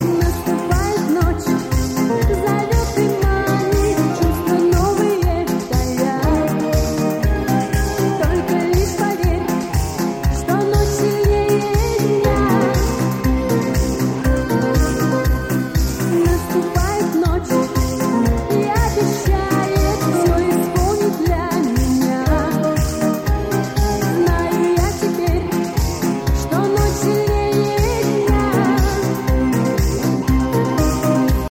женский вокал
90-е
танцевальные
ретро
дискотека 90-х